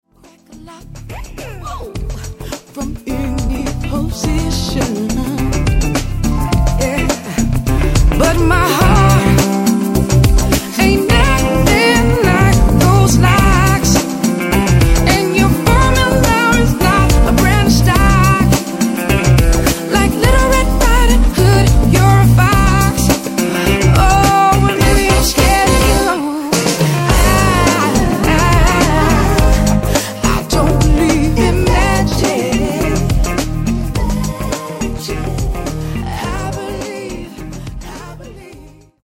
」は、アーバン・スムースな仕上がりとなっている。